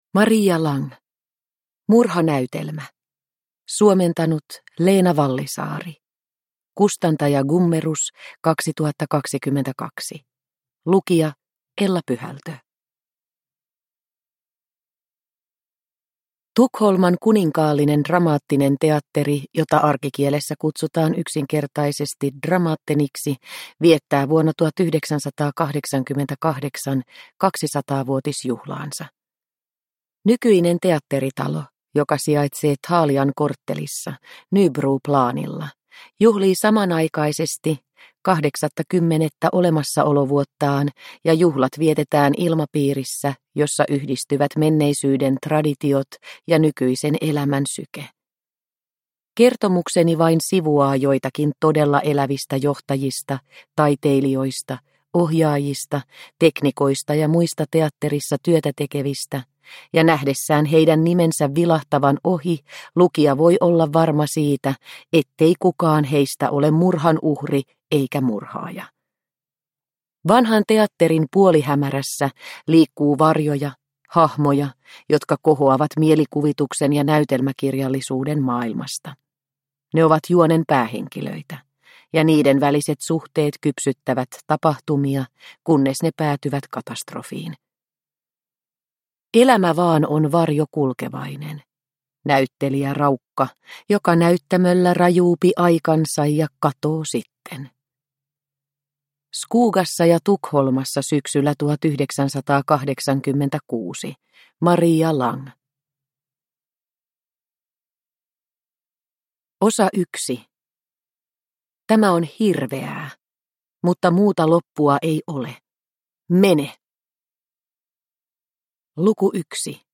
Murhanäytelmä – Ljudbok – Laddas ner